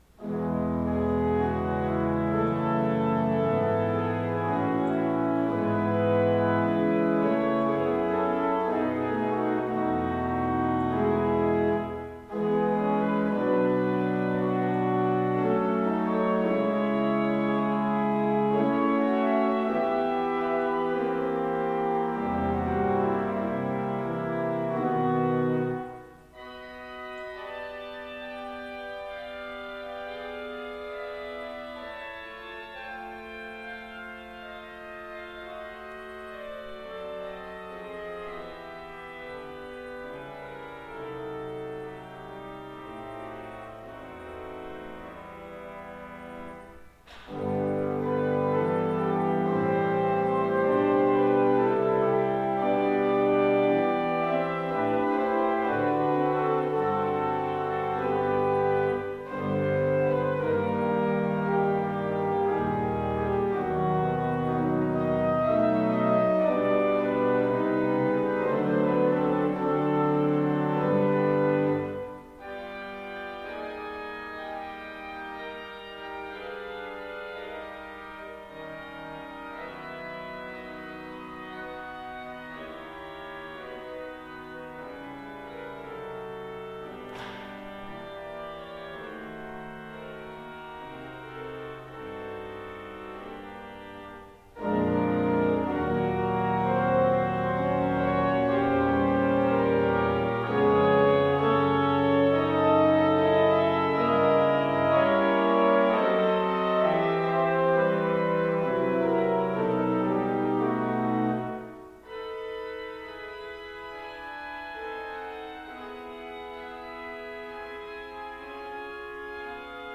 Instrument: Organ
Style: Classical